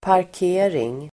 Uttal: [park'e:ring]